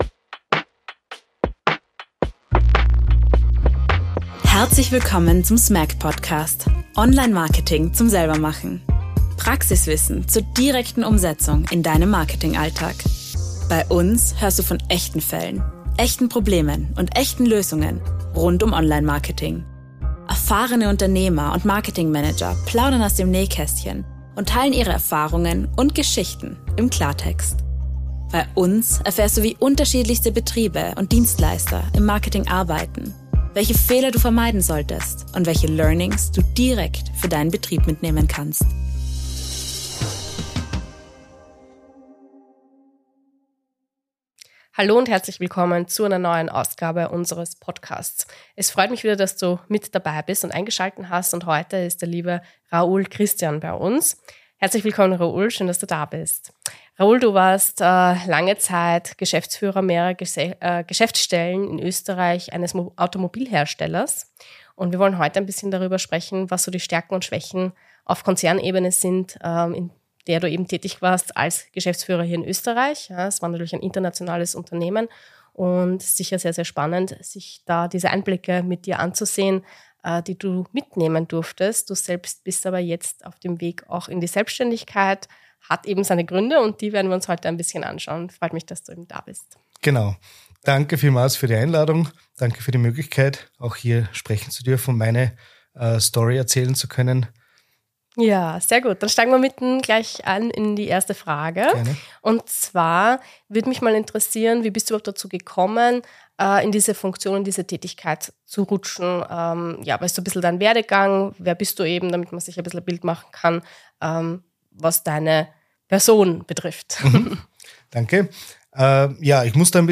Ein Gespräch über Eigenverantwortung, Unternehmertum und den Mut, alte Strukturen zu verlassen.